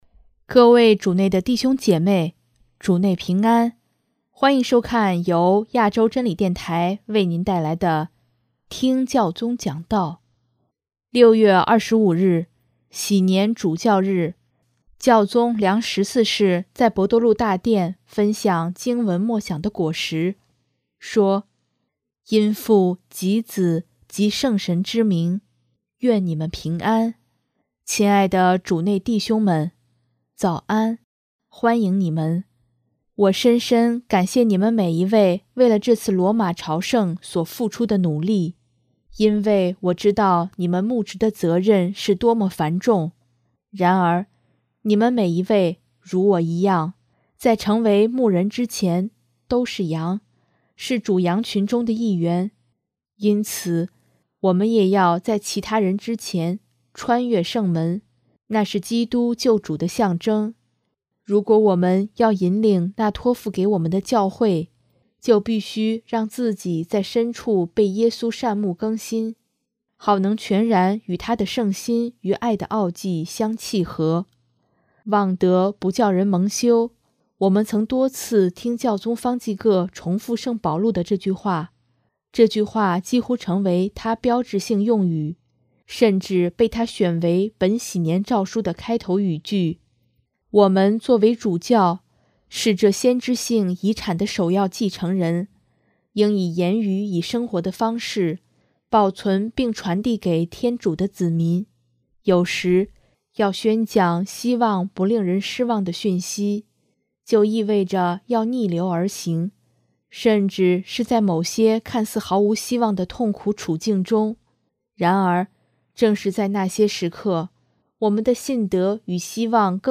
6月25日，禧年主教日，教宗良十四世在圣伯多禄大殿分享经文默想的果实，说：